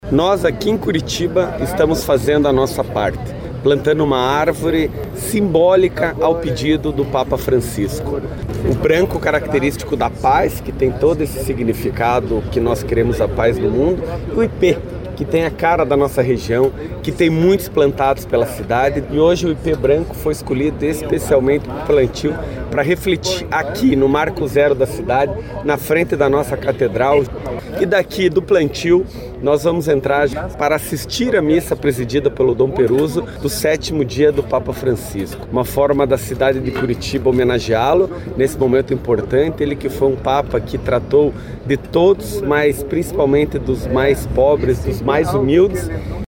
Eduardo Pimentel lembrou que o Papa Francisco sempre foi um defensor do meio ambiente e da sustentabilidade, além de ter feito um pedido para que árvores fossem plantadas ao redor do mundo.